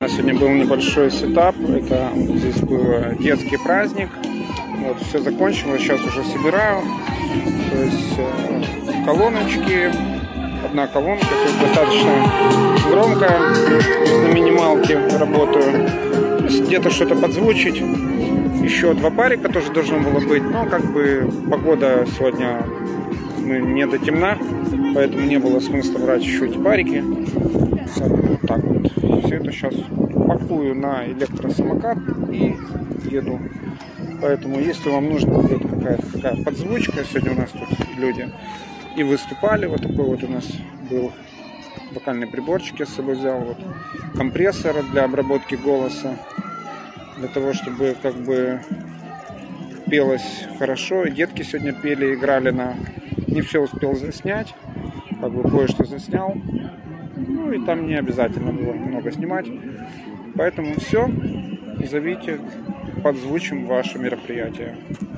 Мини-сетап для детского праздника